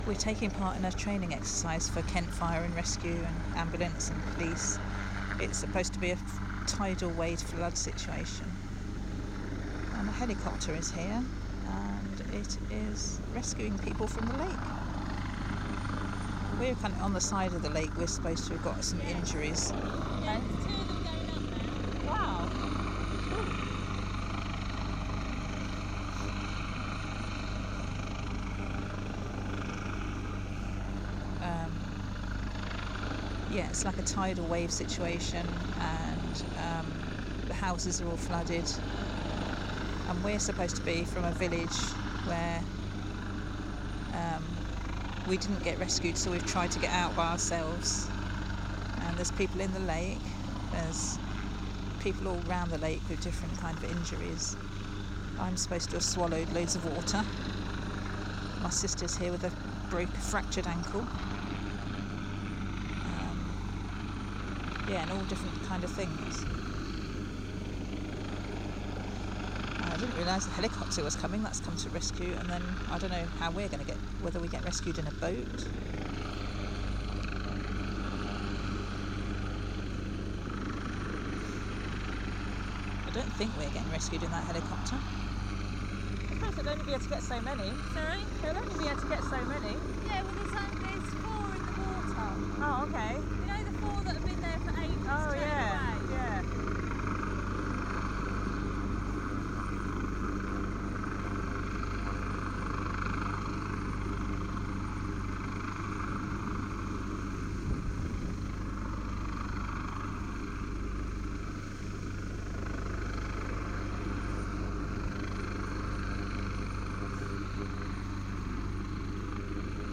Taking part in a training exercise for the emergency services.